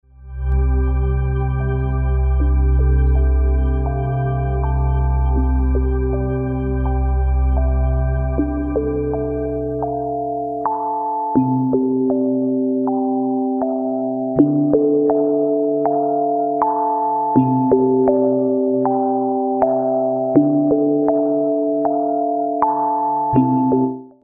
Przepiękna harmonijna muzyka do masażu.